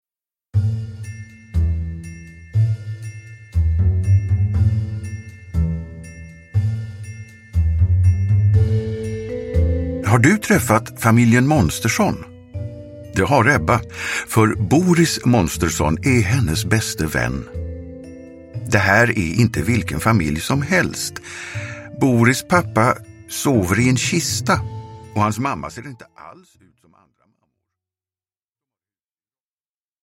Gamen Polly rymmer – Ljudbok – Laddas ner